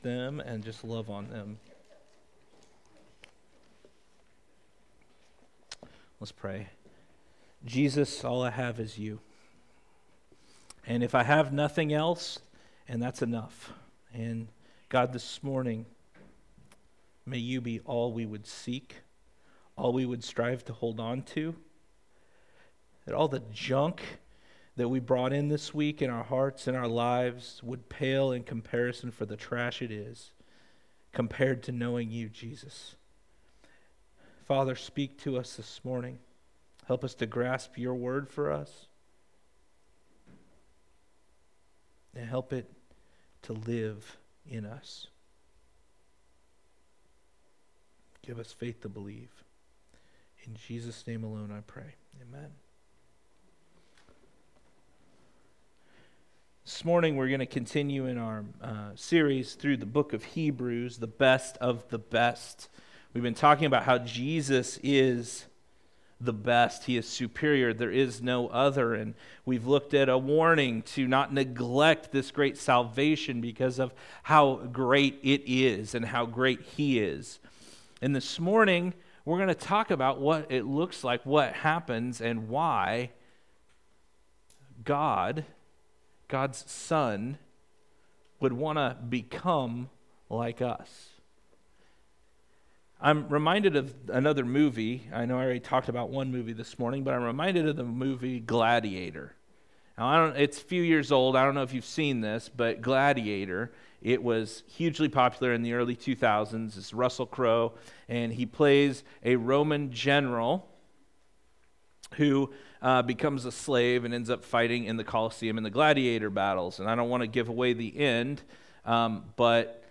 Weekly messages/sermons from GFC Ashton.